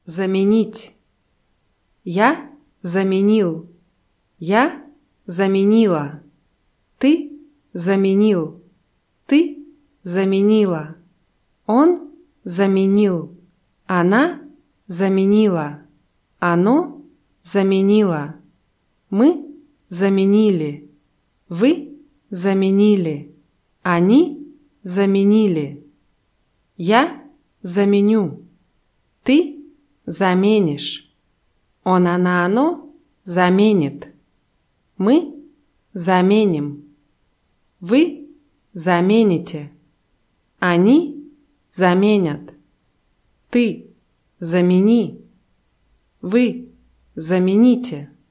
заменить [zamʲinʲítʲ]